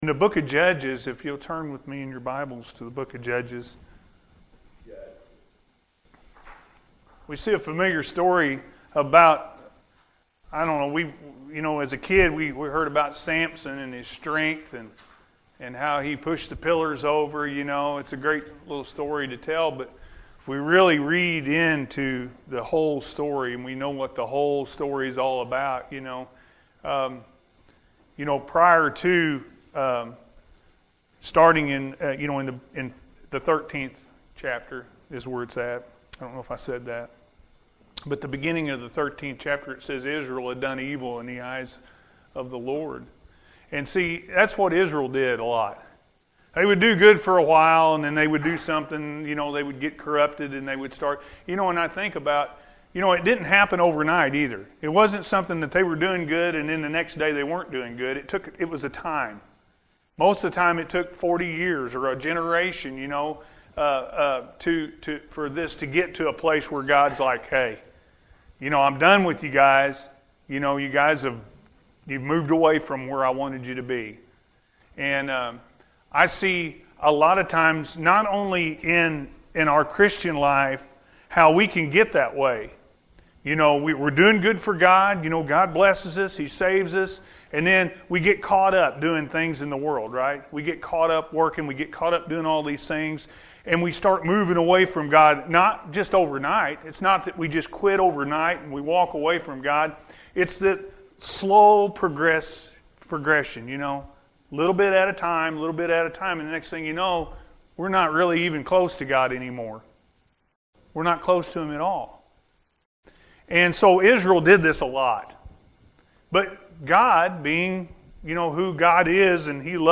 Spiritual Warfare-A.M. Service – Anna First Church of the Nazarene